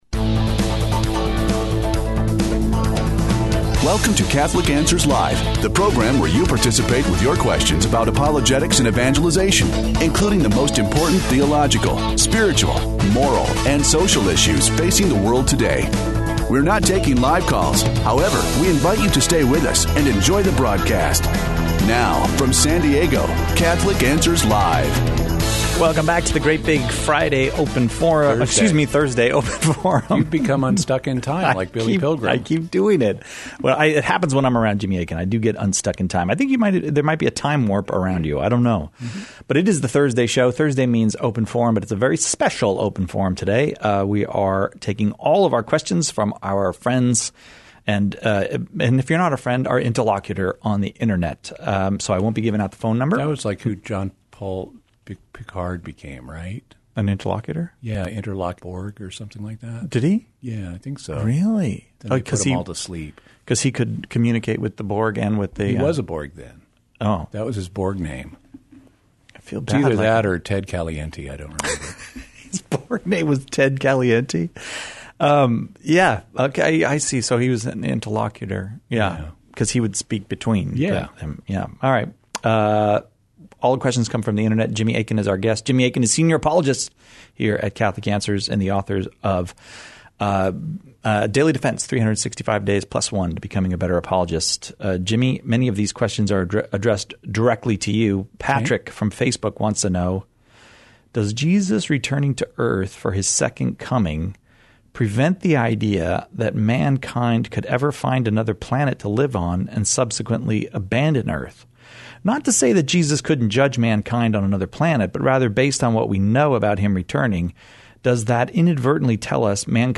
This hour is a special open forum for our Internet viewers. Any question is welcome, but no calls.